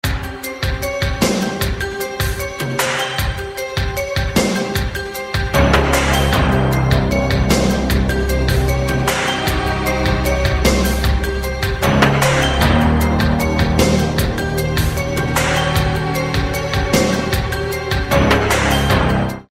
Category: Theme songs